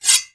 kn-deploy.wav